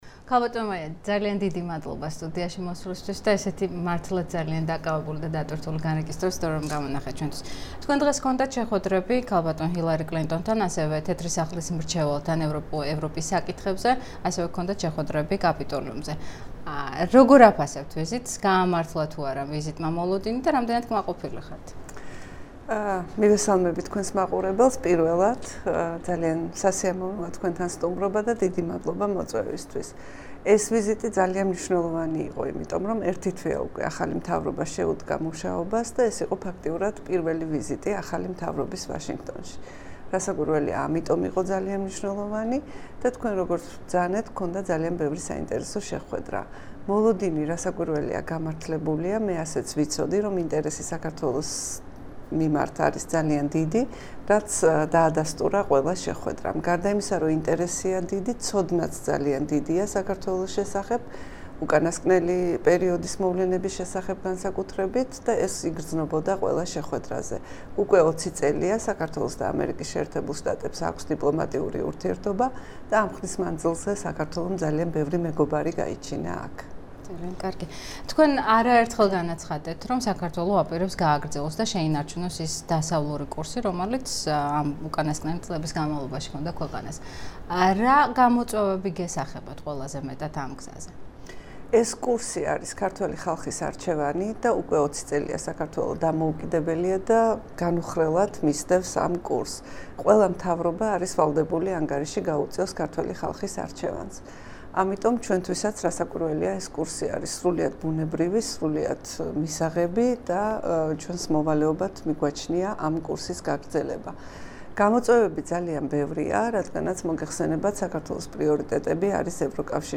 ინტერვიუ ქალბატონ მაია ფანჯიკიძესთან